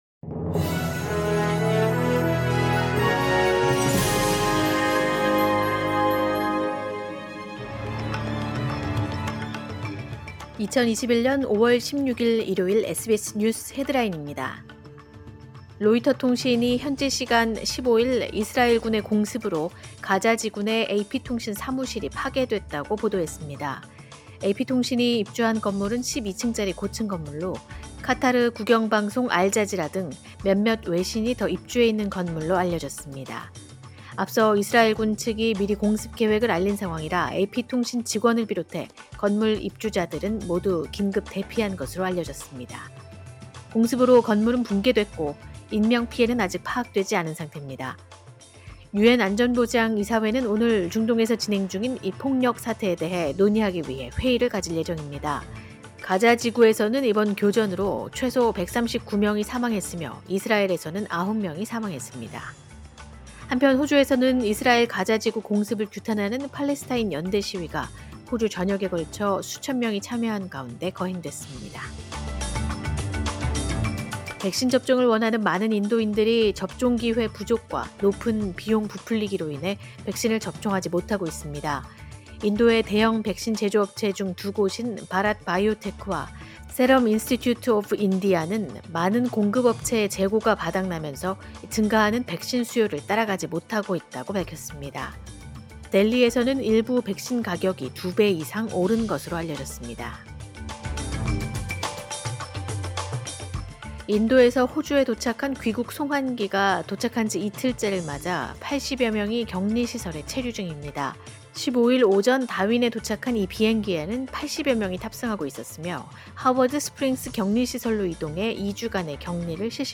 2021년 5월 16일 일요일 SBS 뉴스 헤드라인입니다.